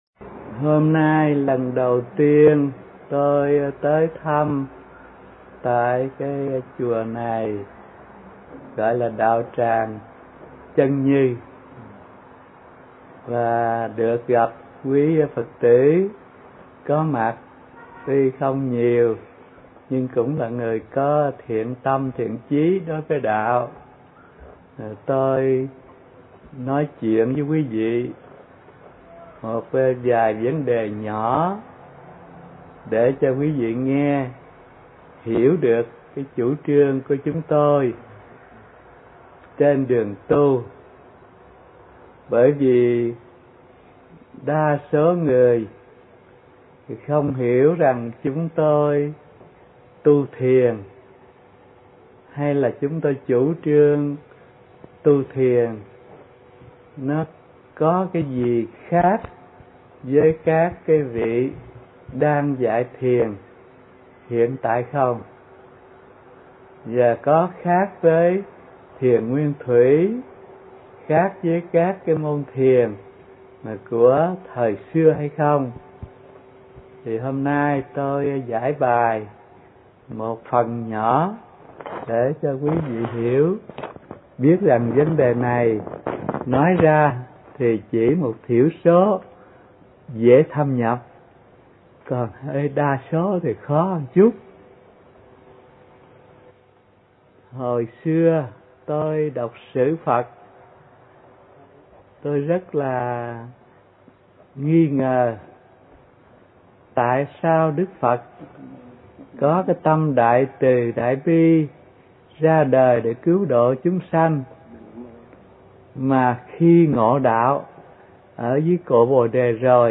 Mp3 thuyết pháp Nói Về Thiền – thầy Thích Thanh Từ